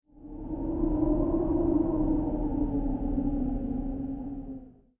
windgust1.wav